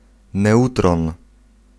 Ääntäminen
Ääntäminen Paris: IPA: [nø.tʁɔ̃] France (Île-de-France): IPA: /nø.tʁɔ̃/ Haettu sana löytyi näillä lähdekielillä: ranska Käännös Konteksti Ääninäyte Substantiivit 1. neutron {m} fysiikka Suku: m .